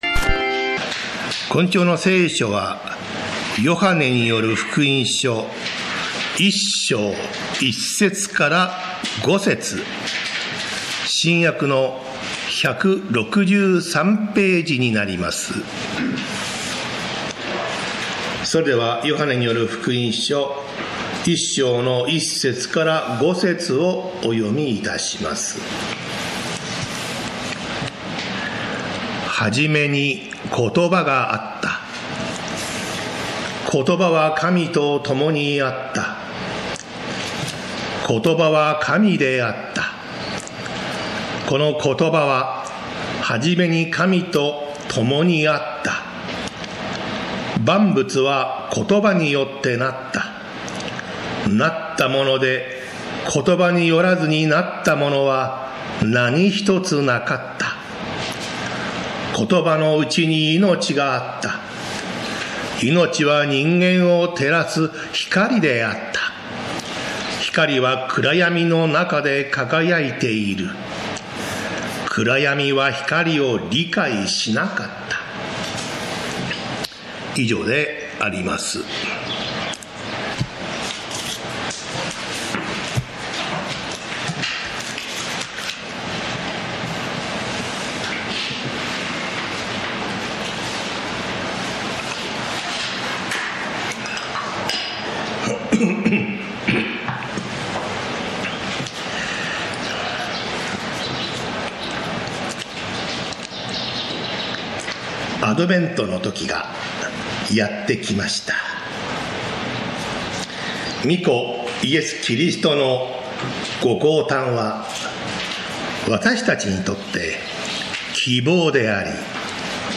礼拝説教アーカイブ 日曜 朝の礼拝